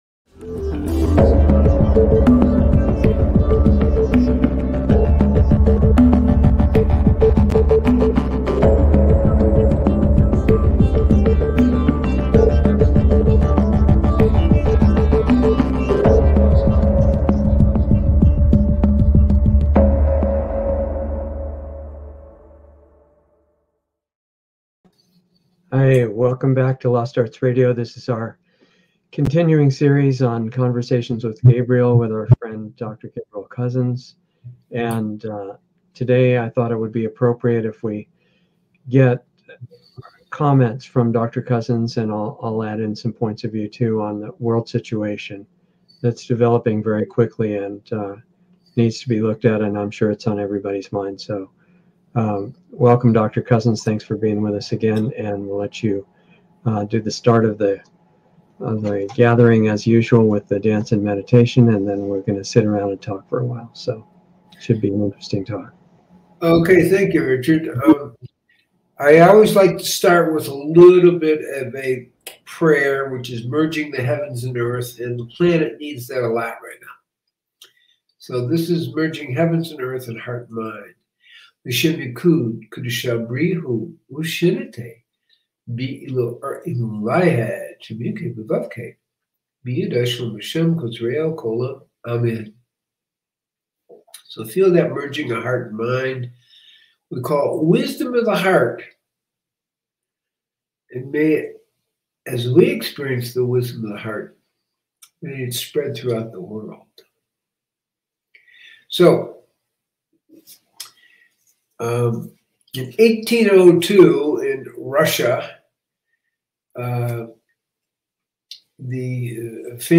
Lost Arts Radio Live - Conversations